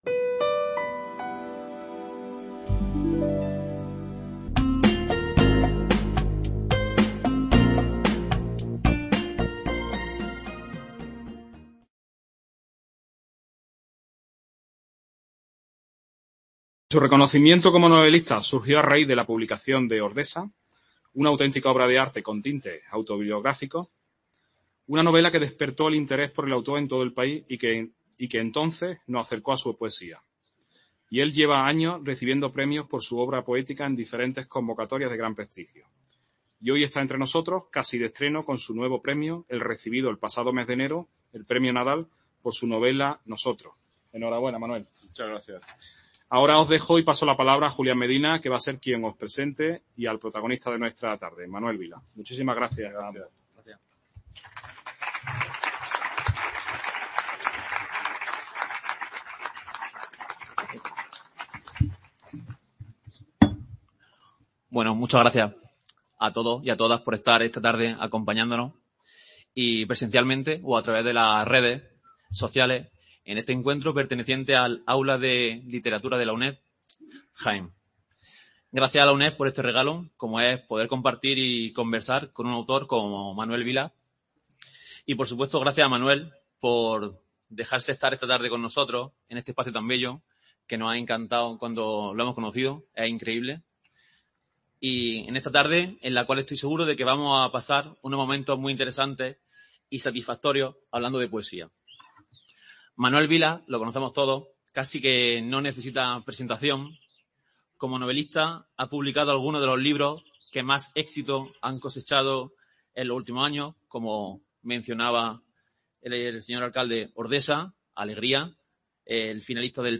Encuentro literario con el escritor Manuel Vilas, que presenta su libro "Una sola vida", dentro de la VI edición del AULA DE LITERATURA que el Centro Asociado de la UNED “Andrés de Vandelvira” de la provincia de Jaén organiza en colaboración con la Diputación Provincial de Jaén y los Ayuntamientos de Alcalá la Real, Andújar, Linares, Jaén y Úbeda. Un diálogo moderado